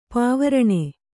♪ pāvaraṇe